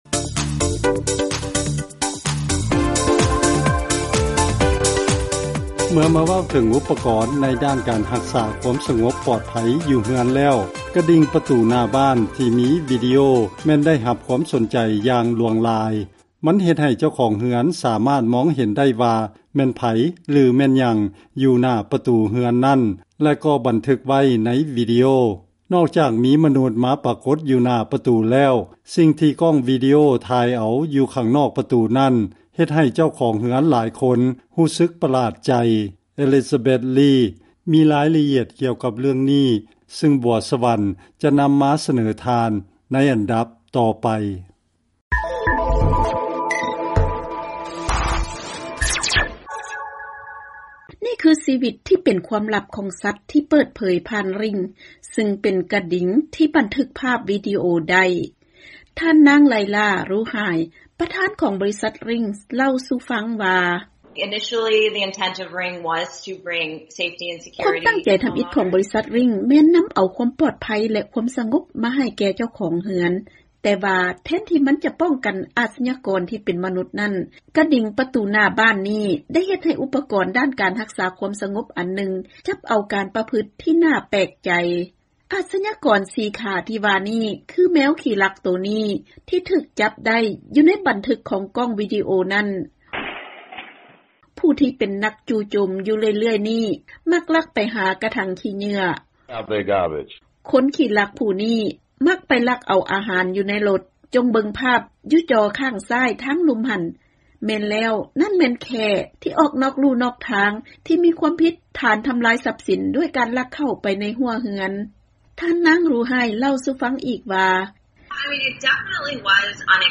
ເຊີນຟັງລາຍງານເລື້ອງກະດິງປະຕູບ້ານມີກ້ອງວີດິໂອຈັບພາບນໍາ